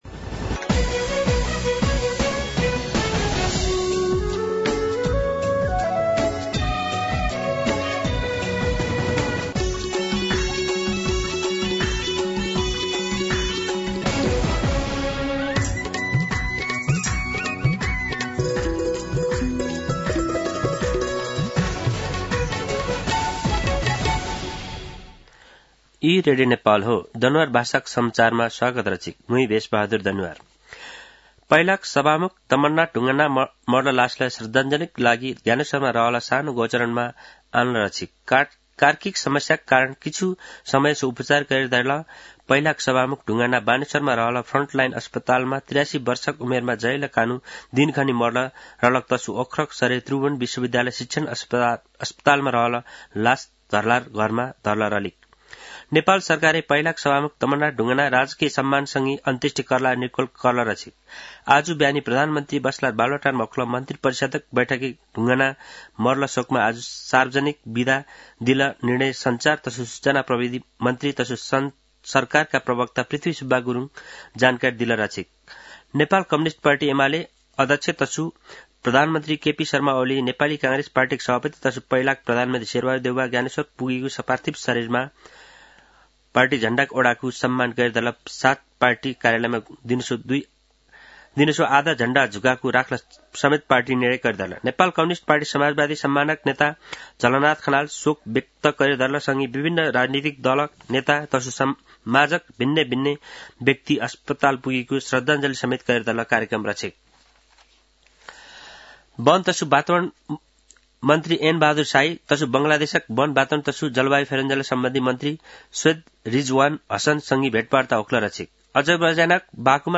दनुवार भाषामा समाचार : ४ मंसिर , २०८१
Danuwar-News-03.mp3